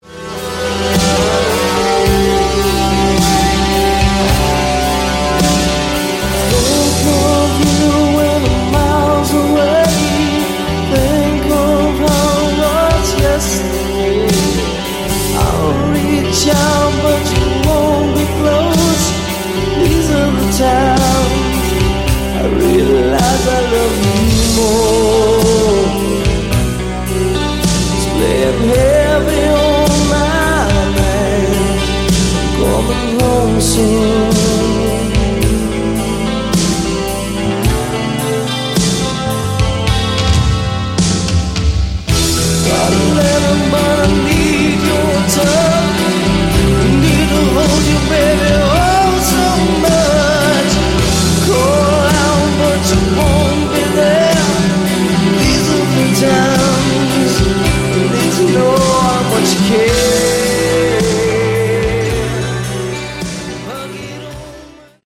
Category: Hard Rock
keyboards
drums